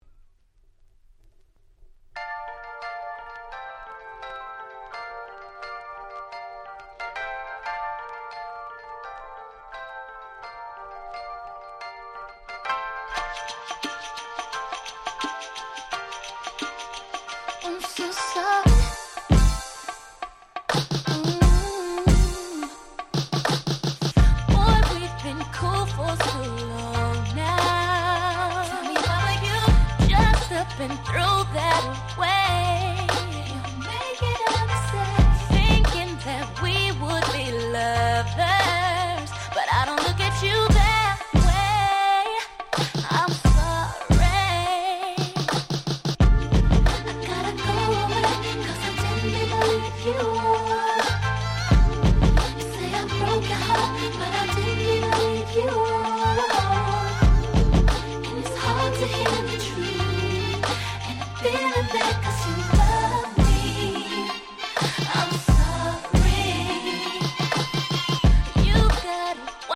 04' Nice R&B !!